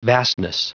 Prononciation du mot vastness en anglais (fichier audio)
Prononciation du mot : vastness